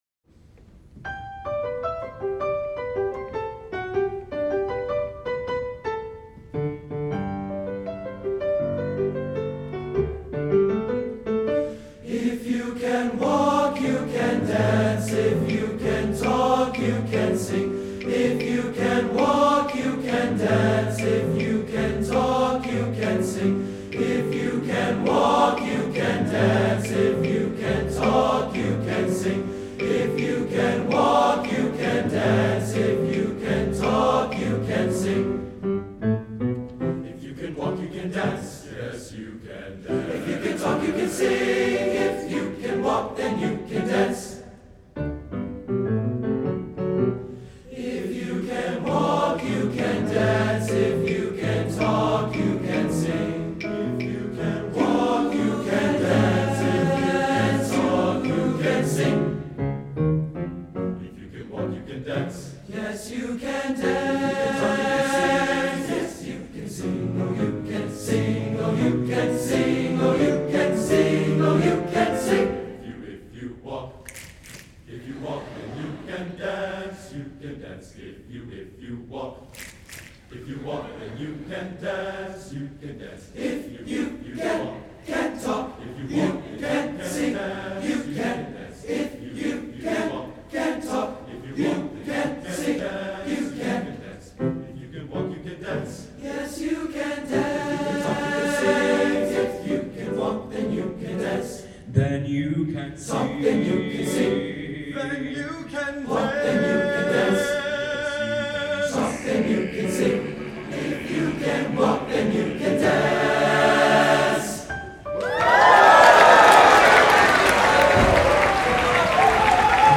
A contagiously joyful Latin dance song.
TBB, piano, claves